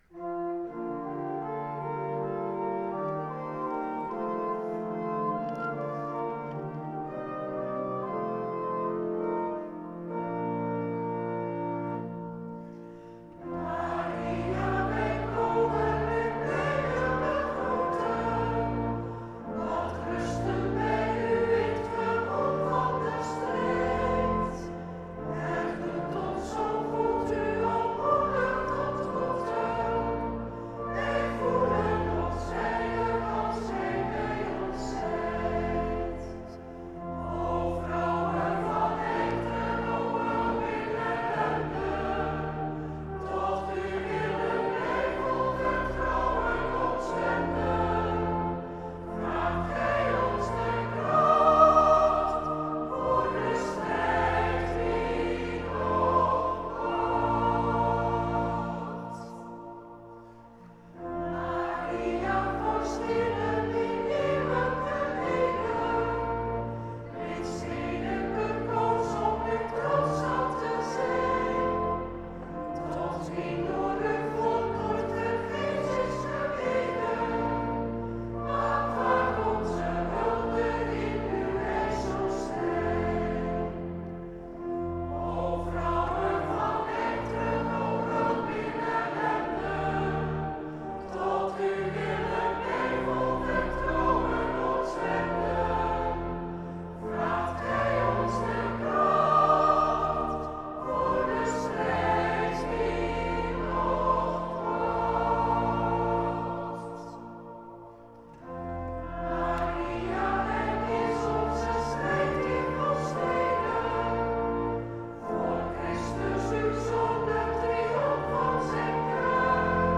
Na de herintroductie van de ommedracht op 24 juni 1936 werd een lied gecomponeerd met als tekst: